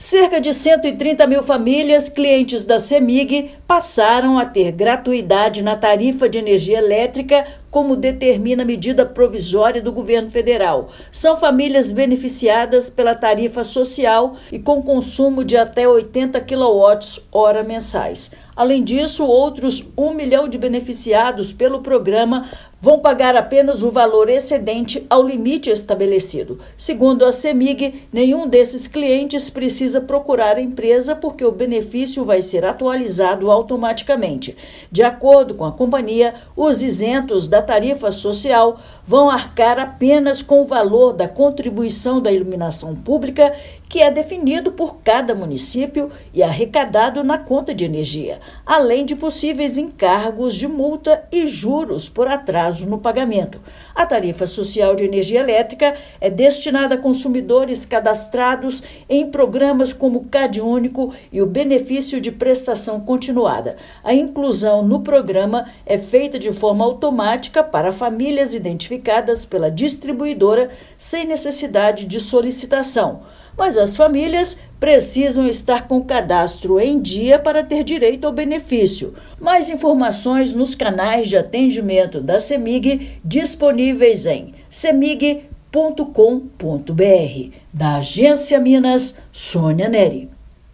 [RÁDIO] Mais de 300 mil famílias de Minas terão isenção da conta de luz por meio da Tarifa Social da Cemig